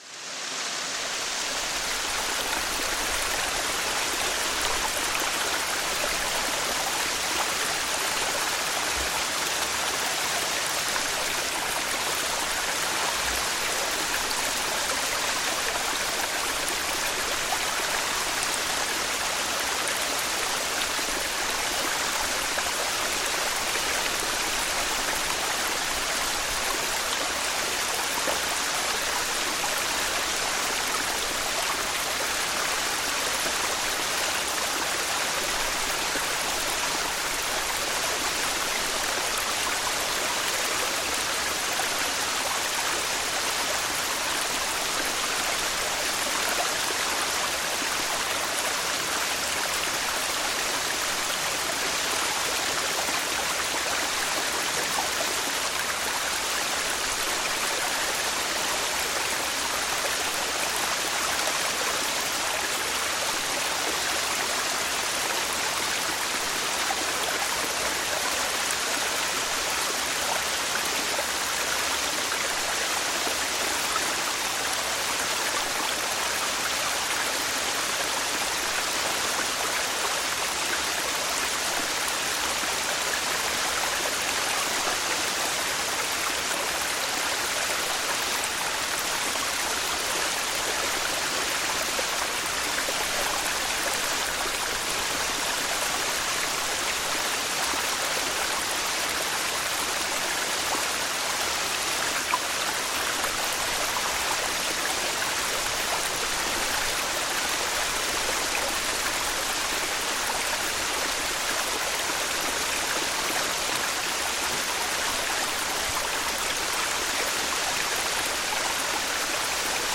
UNENDLICHE WEITE-ENTSPANNUNG: Meeressturm-Donner mit Regen